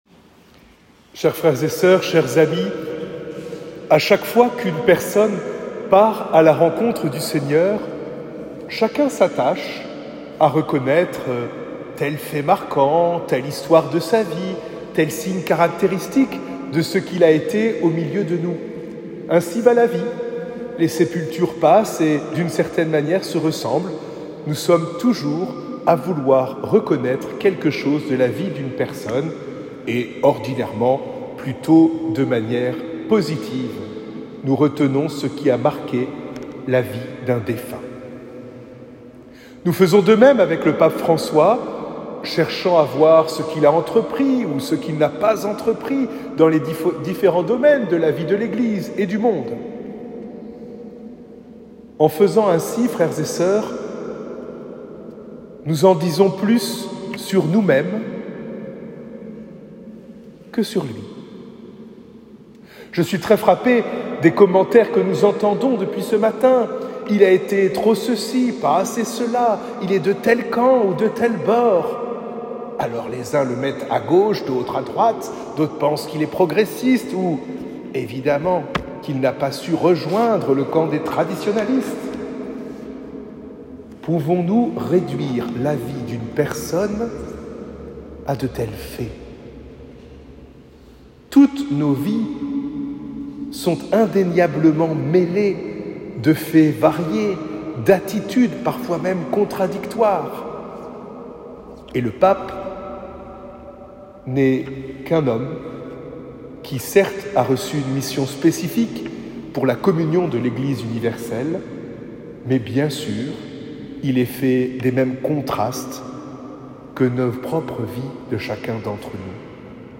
Homélie de la messe d’action de grâce pour le pontificat du pape François 21 (…)
eglise_saint_hilaire.m4a